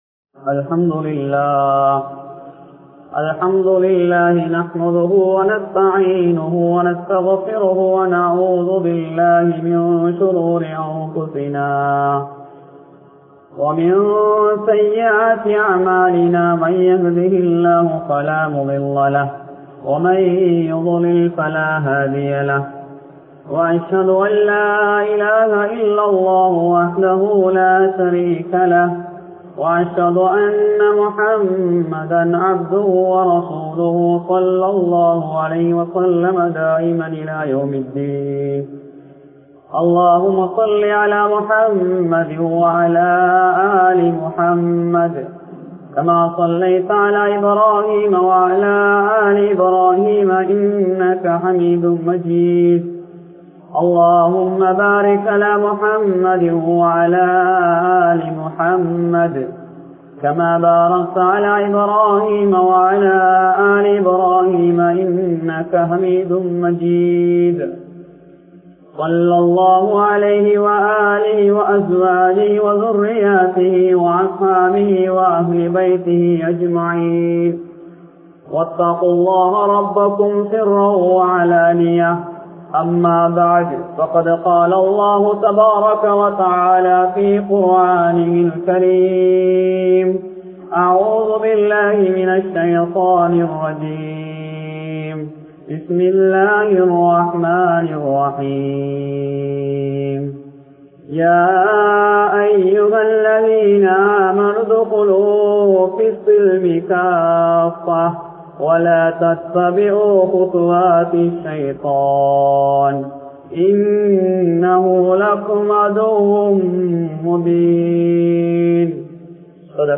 Thaai Naadum Muslimkalin Pangalippum (தாய் நாடும் முஸ்லிம்களின் பங்களிப்பும்) | Audio Bayans | All Ceylon Muslim Youth Community | Addalaichenai
Nelliyagama Jumua Masjidh